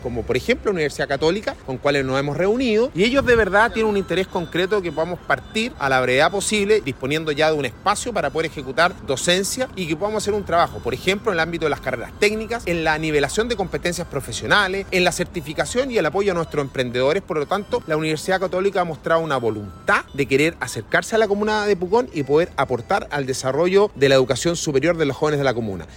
Es por esto que el alcalde de la comuna, Sebastián Álvarez, le comentó a la Radio las conversaciones que mantienen con la Universidad Católica para poder incorporar una sede de esta casa de estudios en Pucón.